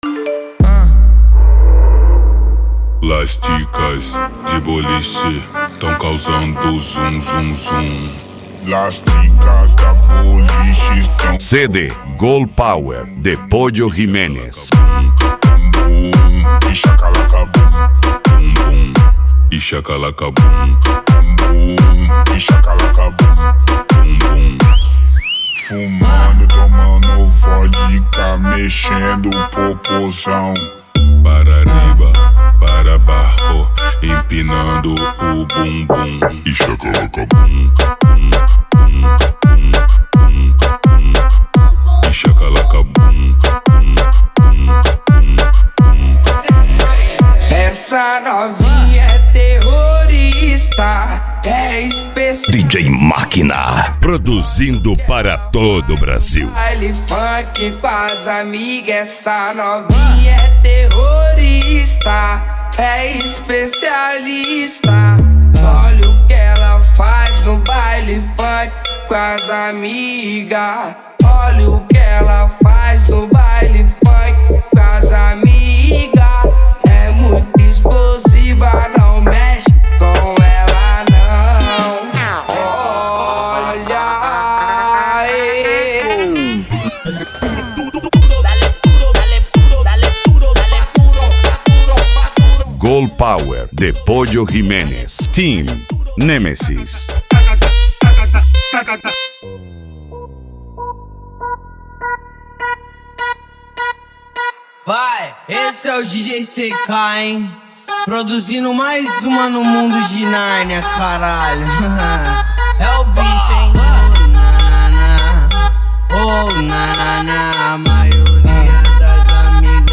Cumbia
Reggae
Reggaeton